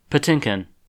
Captions English Audio pronunciation of the surname "Patinkin" in U.S. English (Midwestern accent).
En-us-Patinkin.ogg